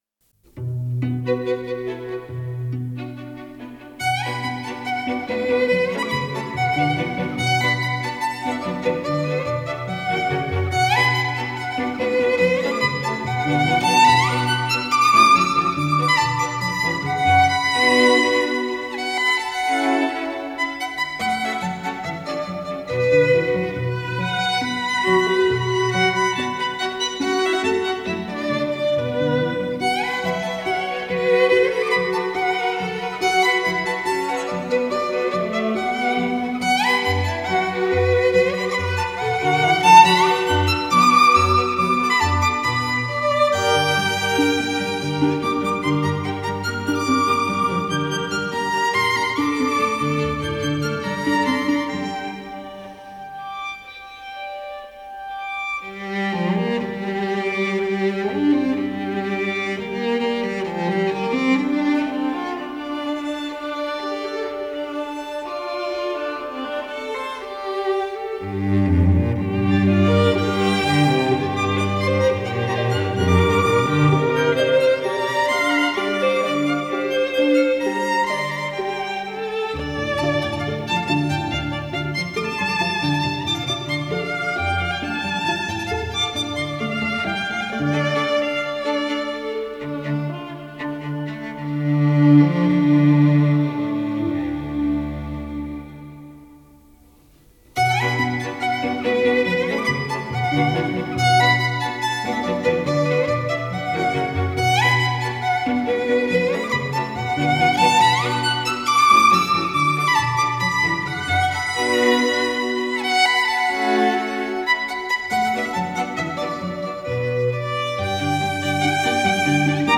那歌声...那旋律...悠扬飘荡...